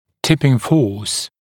[‘tɪpɪŋ fɔːs][‘типин фо:с]опрокидывающая нагрузка